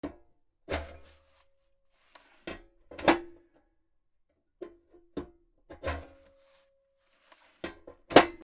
郵便受け 金属 『カタン』